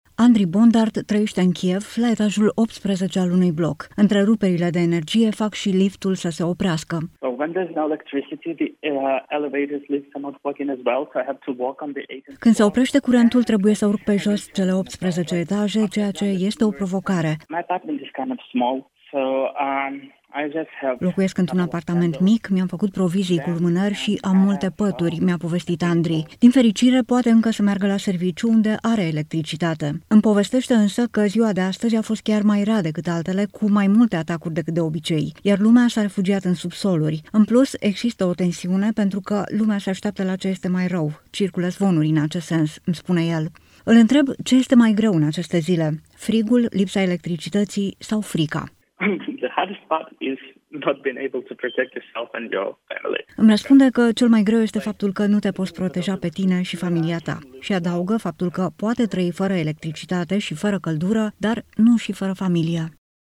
Mărturie din Kiev: „Pot trăi fără electricitate și fără căldură, dar nu și fără familie” | AUDIO